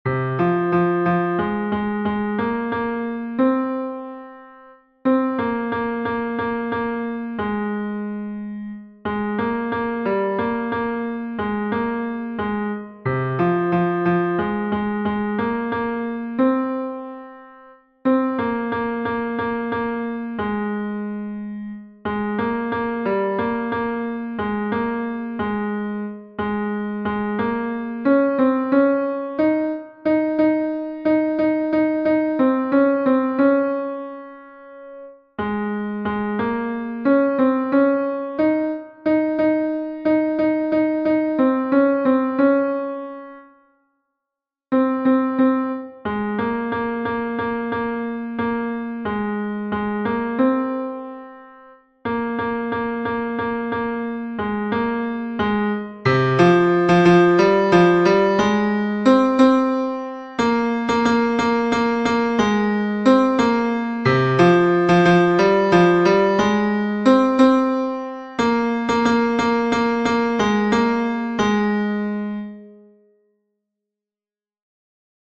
Fichier son ténor 1
Nerea-izango-zen-Laboa-tenor-1-V3-1.mp3